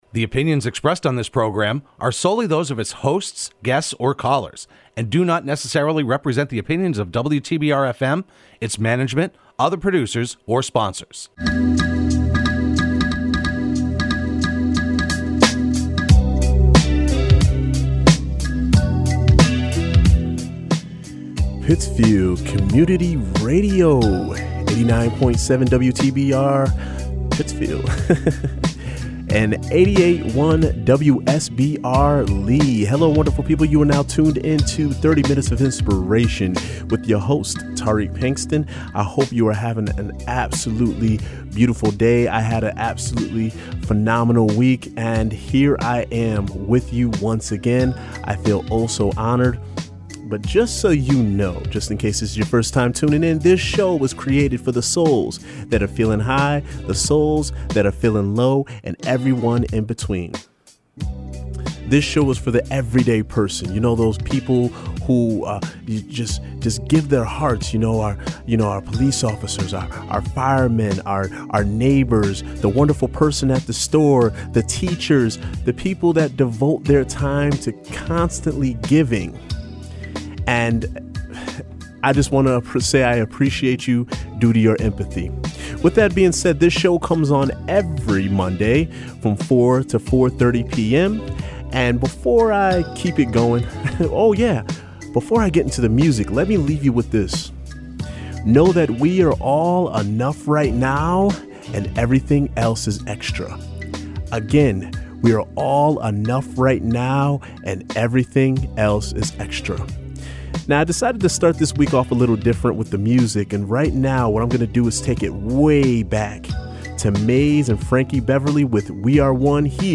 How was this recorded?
broadcast live every Monday afternoon at 4pm on WTBR.